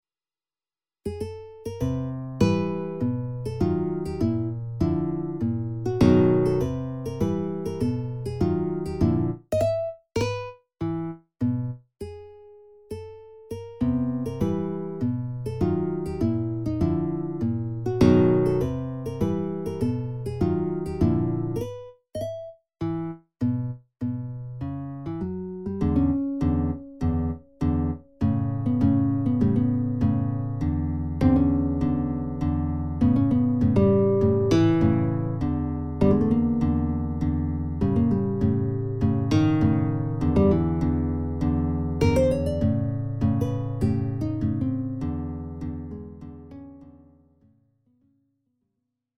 midi sample